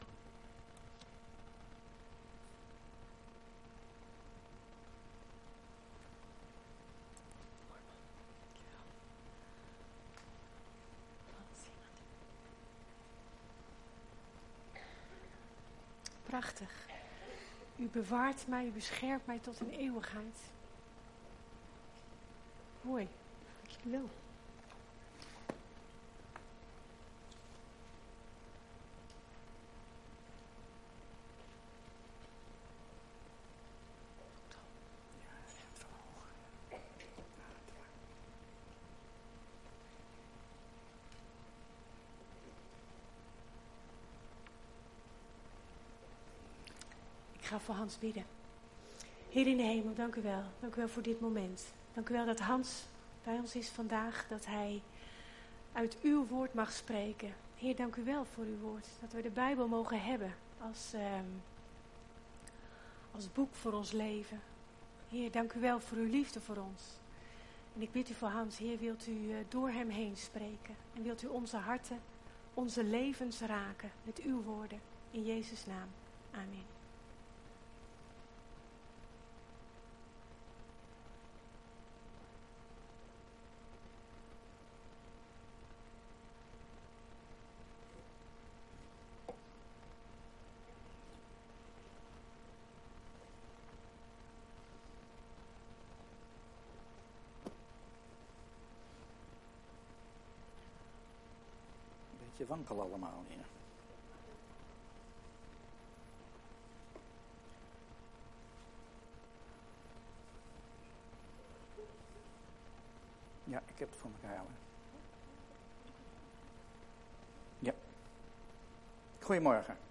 preek
We komen elke zondagmorgen bij elkaar om God te aanbidden.